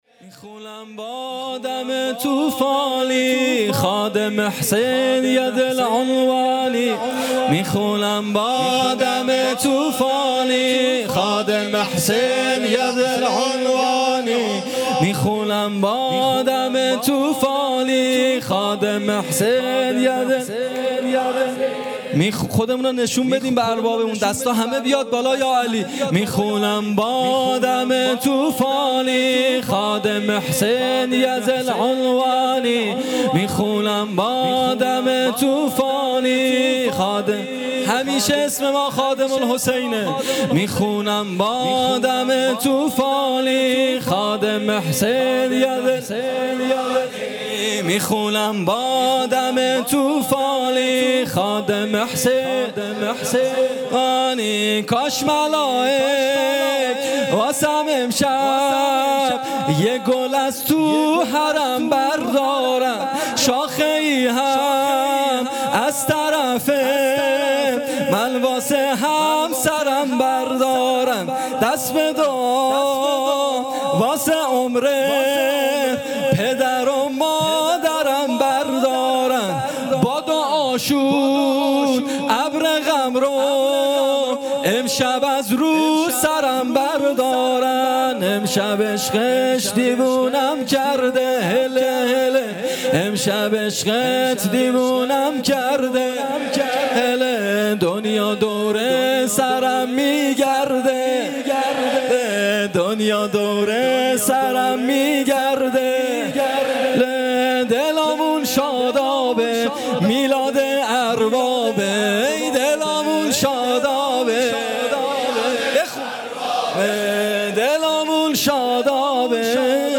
جلسه هفتگی
مراسم ولادت سرداران کربلا